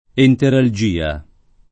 enteralgia [ enteral J& a ]